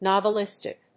音節nov・e・lis・tic 発音記号・読み方
/nὰvəlístɪk(米国英語), n`ɔvəlístɪk(英国英語)/